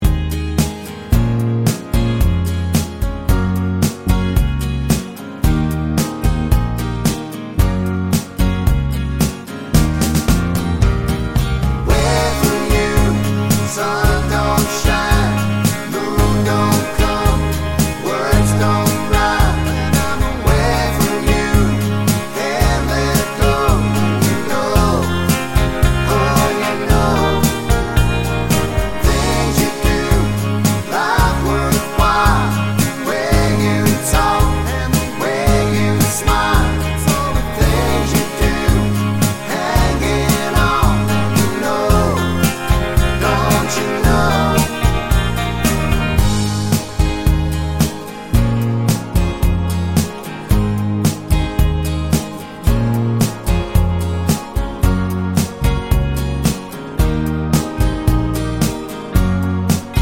no Backing Vocals Country (Male) 3:59 Buy £1.50